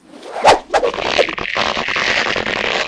tailpull.ogg